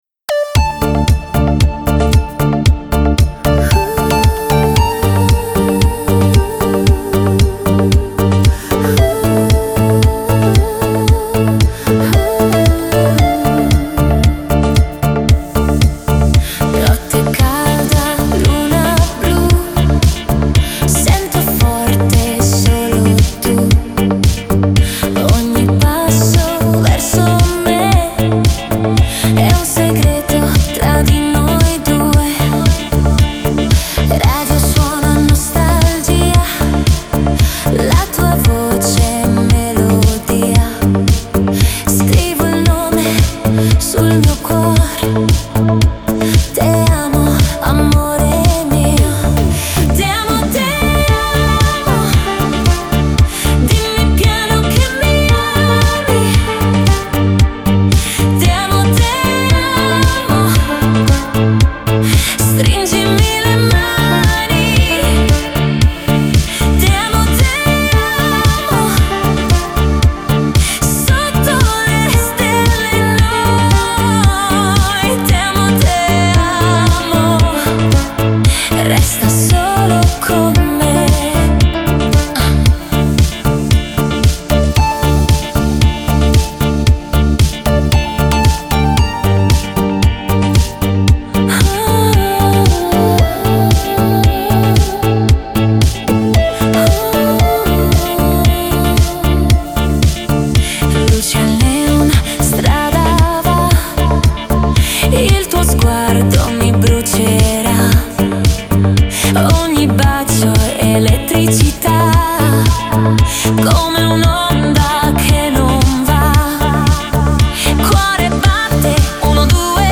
диско , pop
dance , танцевальная музыка , эстрада